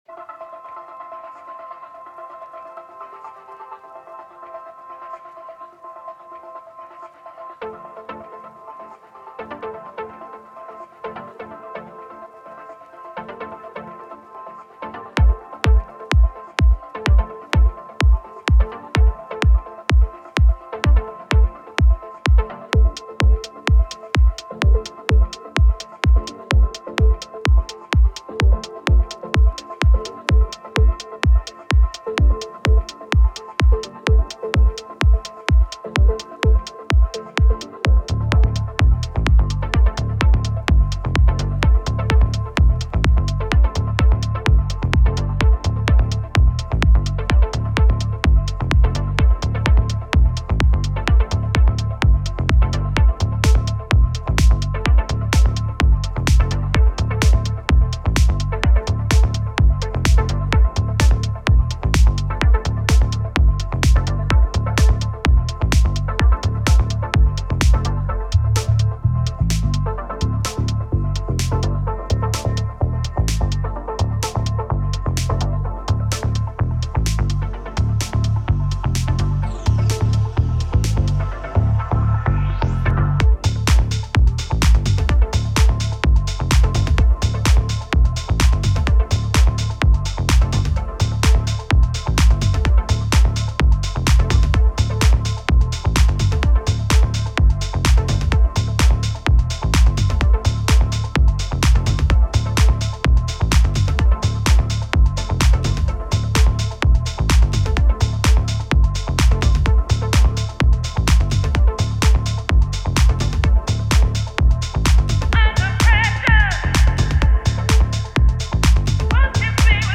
Digging it in your track!
If you’re interested, that’s a CS-80 emulation, layered with a stock Ableton Wavetable Juno string sound, put through a bunch of FX.